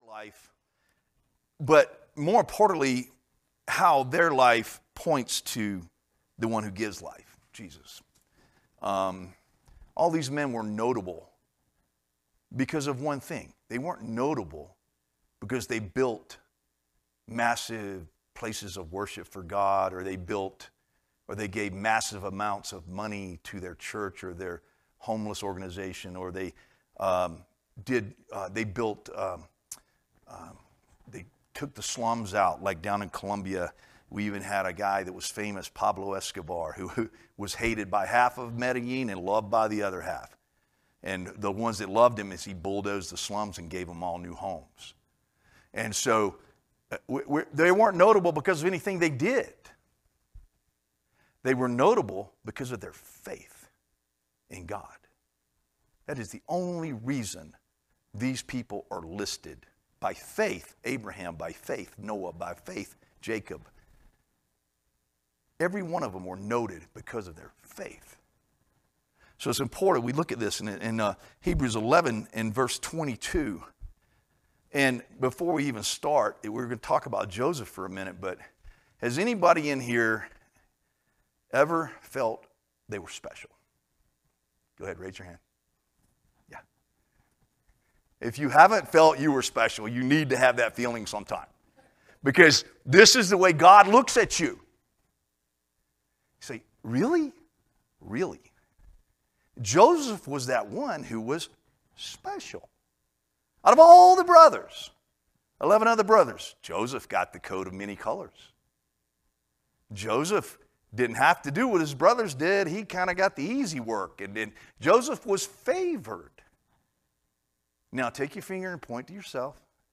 teaches from the Book of Hebrews, Chapter 11